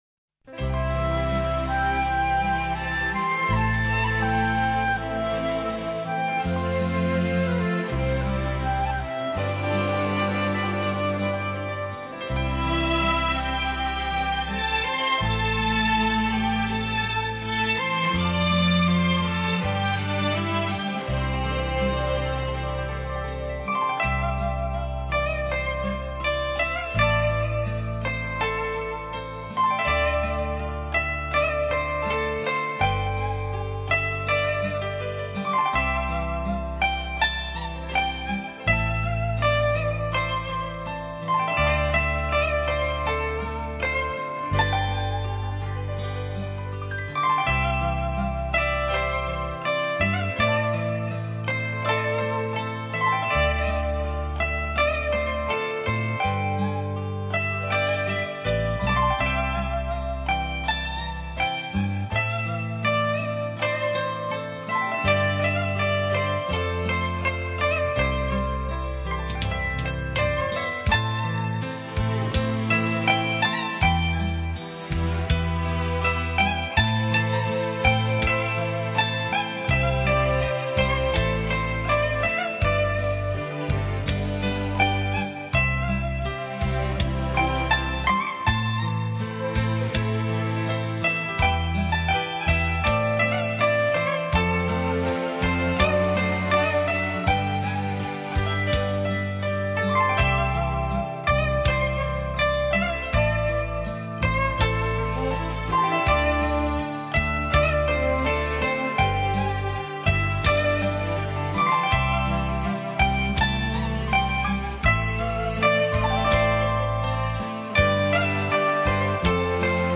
·所属分类：音乐:轻音乐
筝之美，其音纯、色素、意远。
古筝的古朴典雅、恬静柔美，让人荡气回肠，回味无穷。